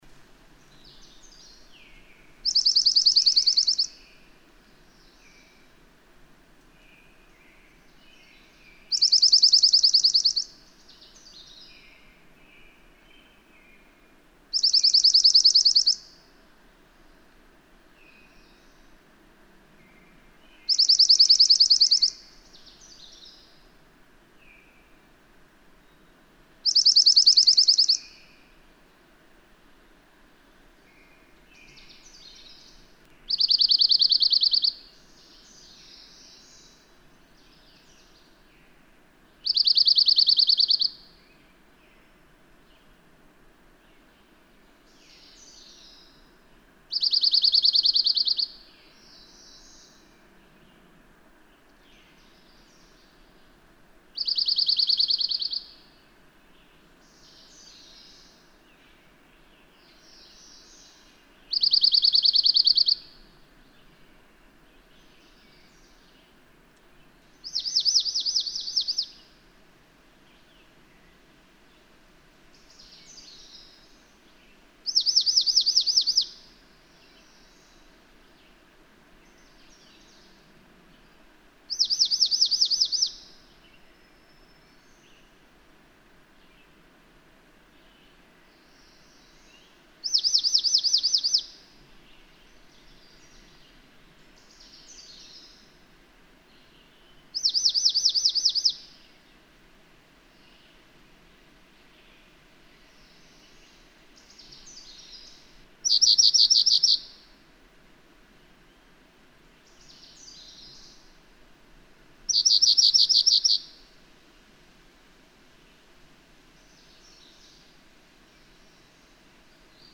♫187: Five renditions of each of four different songs from a dark-eyed junco, spliced together from a much longer singing sequence over about an hour.
Grindstone Campground, Mt. Rogers, Virginia.
187_Dark-eyed_Junco.mp3